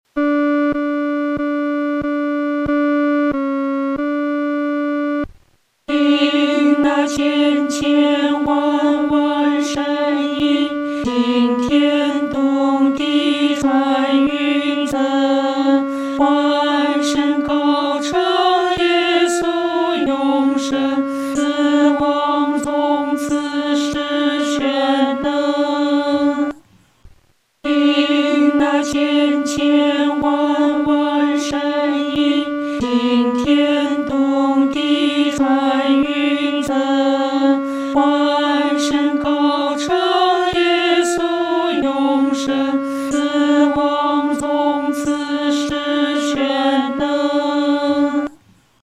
女低合唱